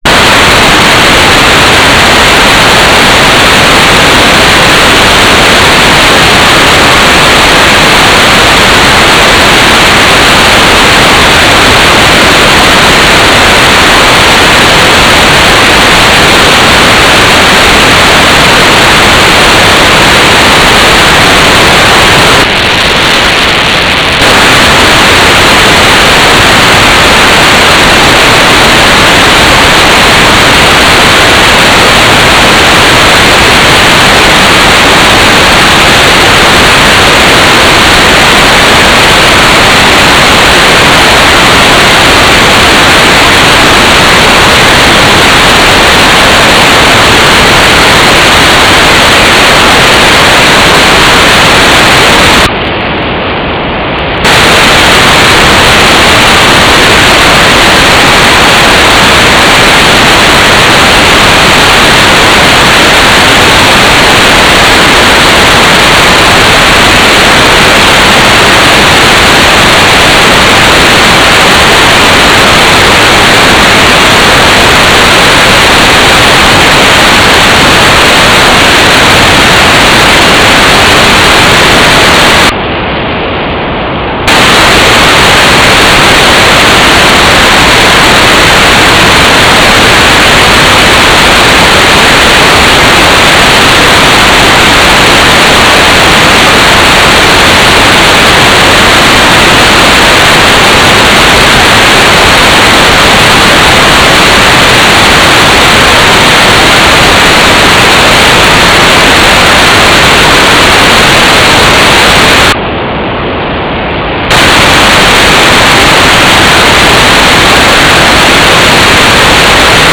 "transmitter_description": "4k8 GMSK USP",
"transmitter_mode": "GMSK",